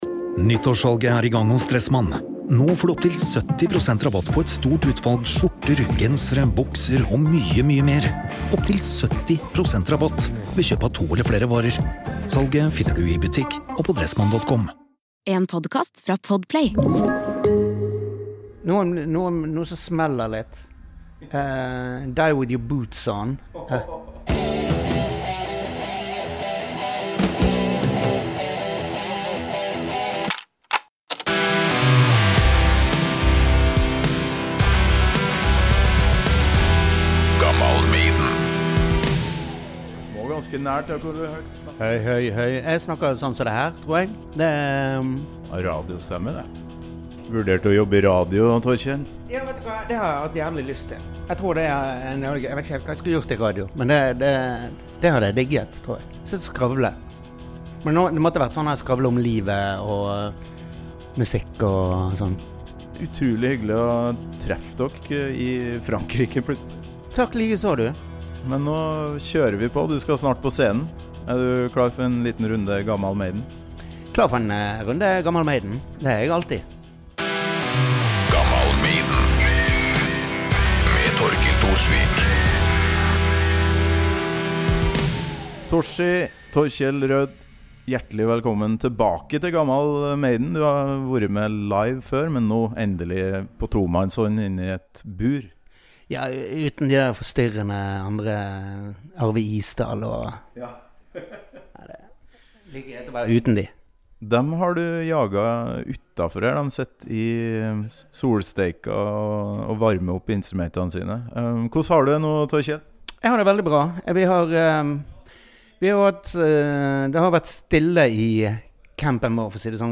Denne gangen er vi (nesten) dønn alene i en liten bod backstage i Frankrike.
Intervjuet ble gjort i høst da jeg møtte bandet Audrey Horne på den nordfranske festivalen Raismes Fest.